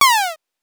8 bits Elements / laser shot
laser_shot_7.wav